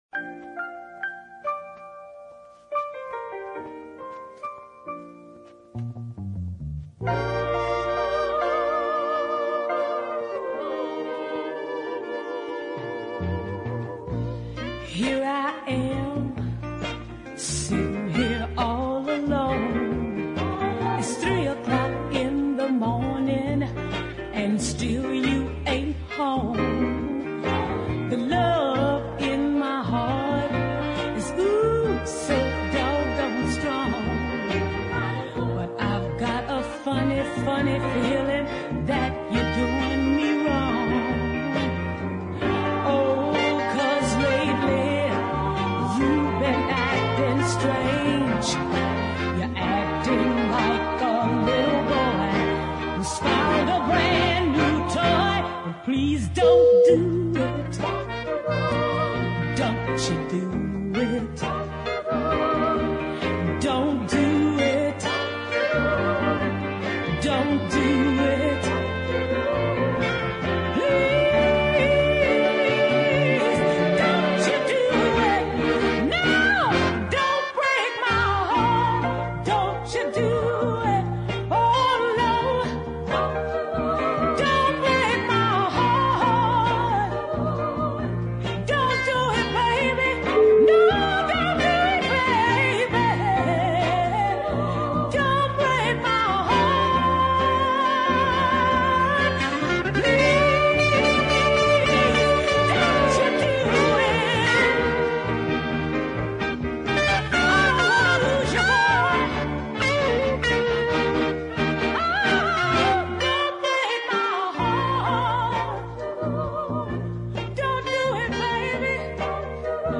deep sultry tone